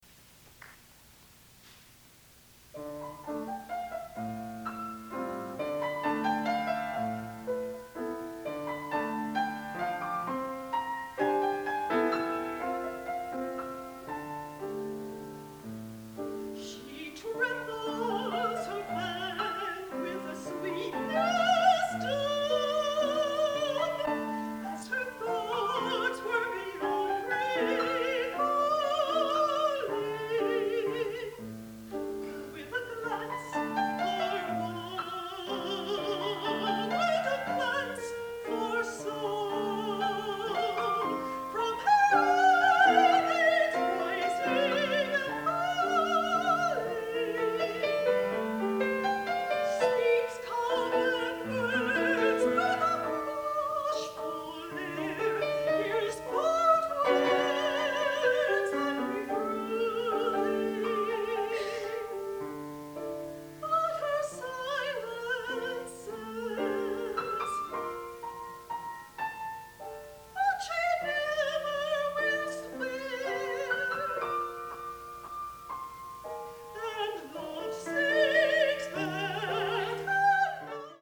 InstrumentationHigh Voice and Piano